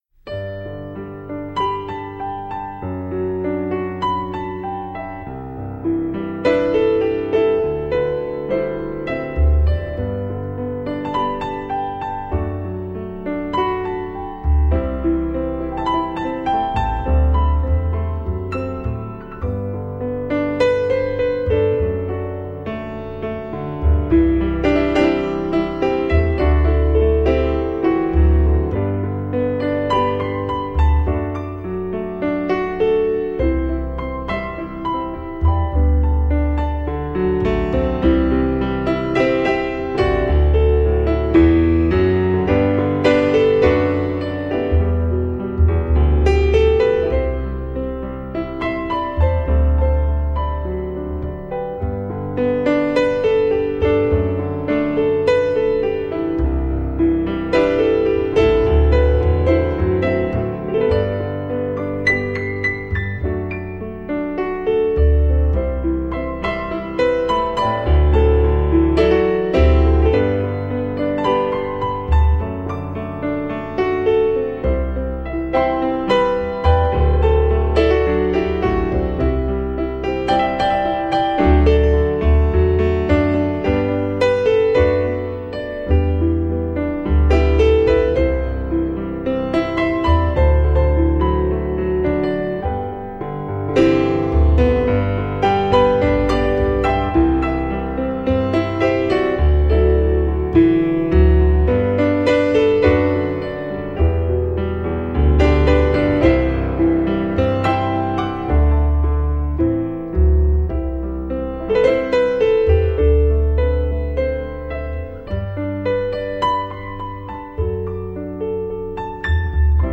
钢琴演奏
用纯净琴音勾起每段甜蜜的回忆，
只让旋律说话、让音符像窗前阳光温暖挪移。